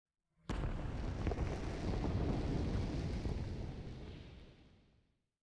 Minecraft Version Minecraft Version latest Latest Release | Latest Snapshot latest / assets / minecraft / sounds / ambient / nether / basalt_deltas / plode1.ogg Compare With Compare With Latest Release | Latest Snapshot